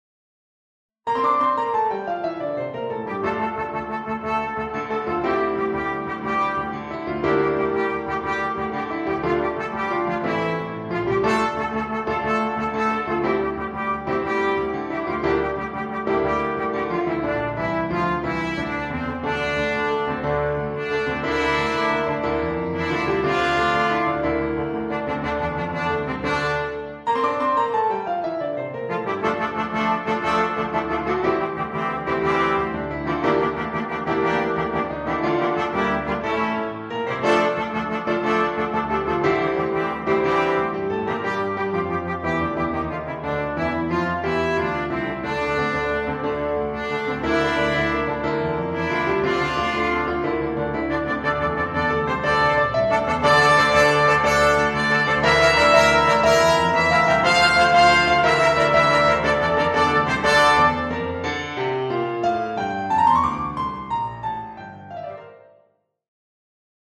A very energetic SATB and piano setting
This has a challenging and driving piano part.